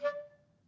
Clarinet
DCClar_stac_D4_v1_rr1_sum.wav